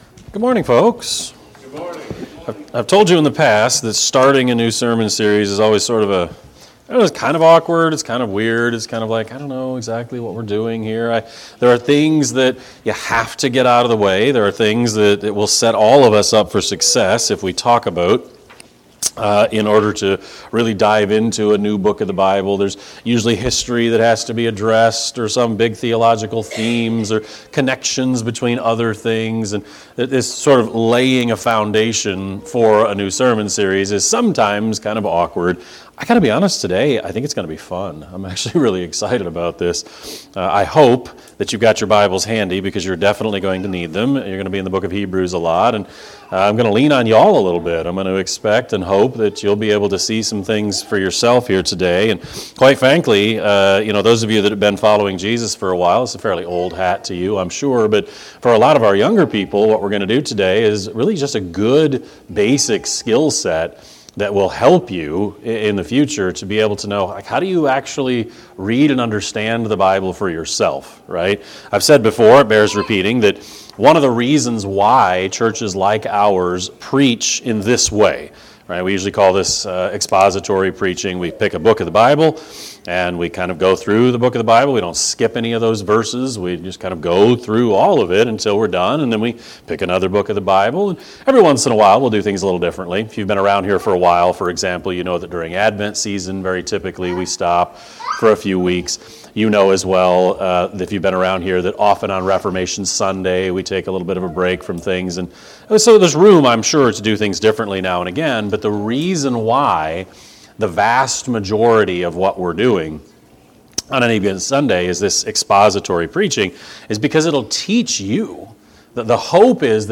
Sermon-5-18-25-Edit.mp3